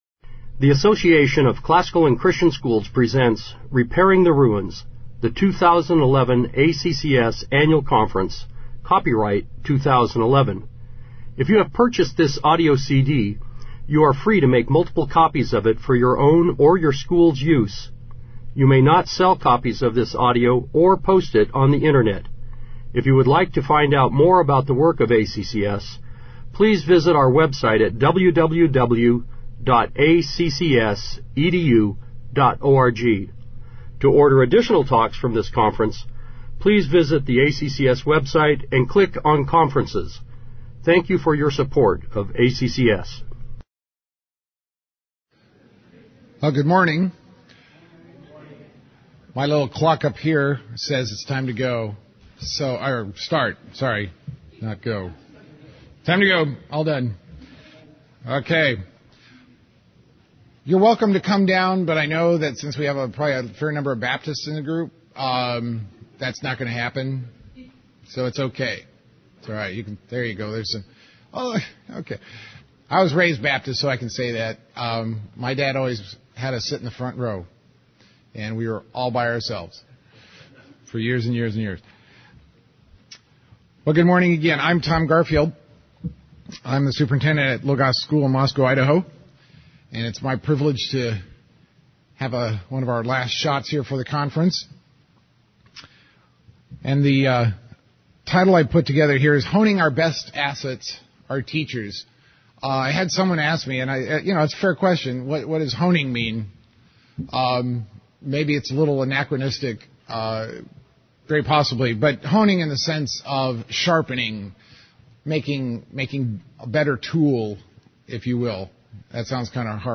2011 Workshop Talk | 1:01:38 | All Grade Levels, Leadership & Strategic, Training & Certification
Additional Materials The Association of Classical & Christian Schools presents Repairing the Ruins, the ACCS annual conference, copyright ACCS.